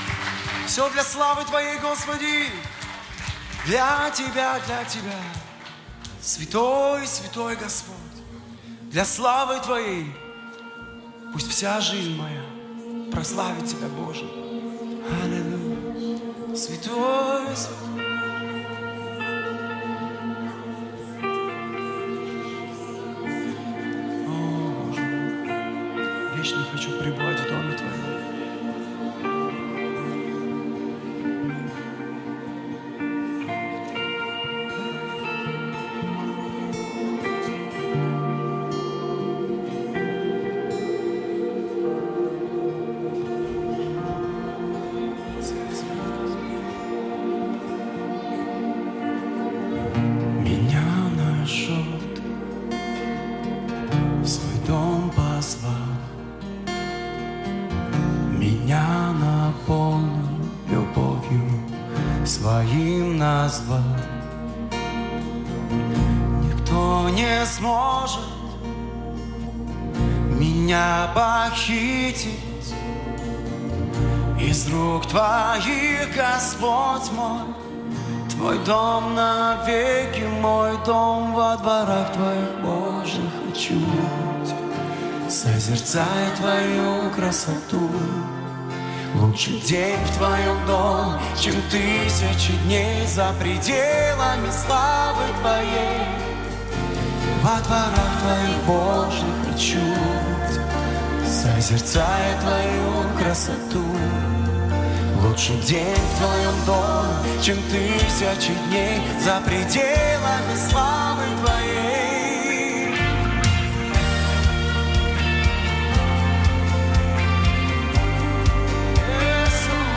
97 просмотров 105 прослушиваний 2 скачивания BPM: 120